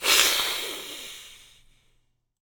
playerKill.ogg